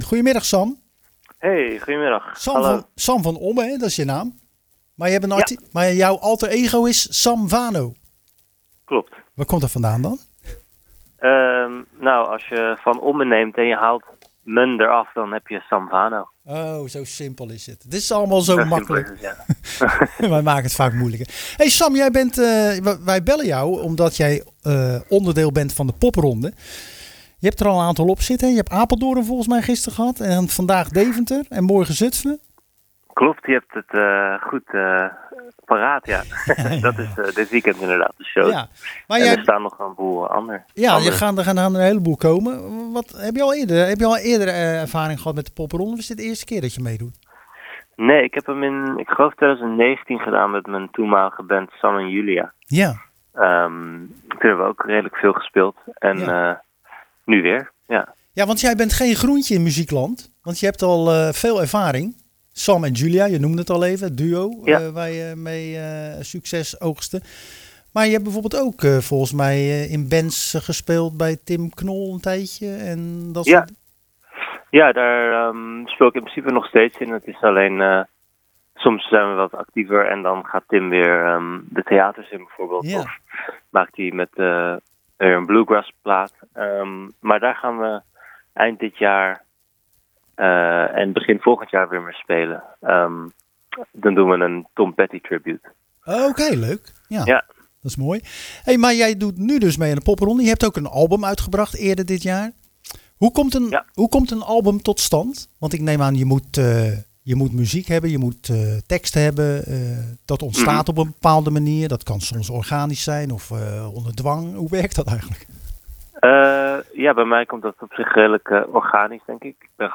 Tijdens het programma Zwaardvis belden we met singer-songwriter